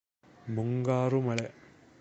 pronunciation transl.